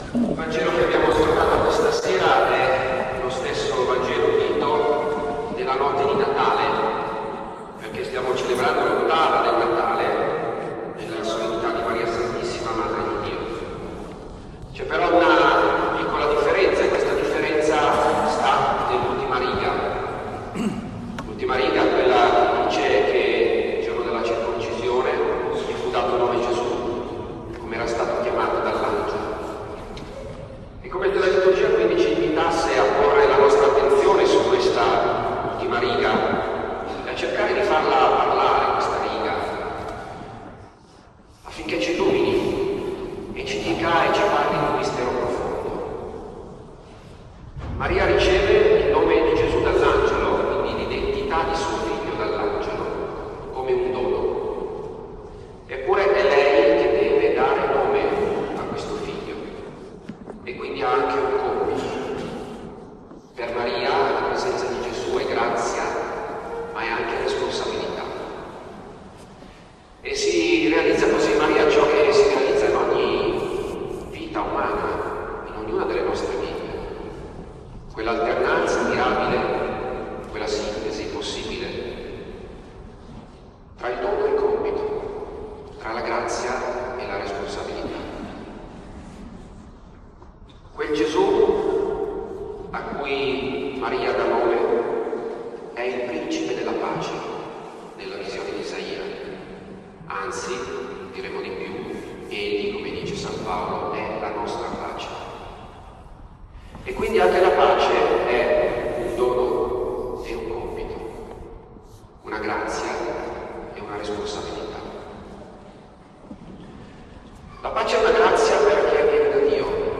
Celebrazione solenne presso la Chiesa Collegiata di San Fiorenzo a Fiorenzuola d’Arda
01 gennaio 2025, celebrazione per la 58esima giornata mondiale della Pace.
omelia-per-la-pace-2.mp3